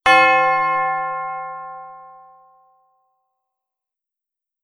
Single-strike-church-bell-sound-effect.wav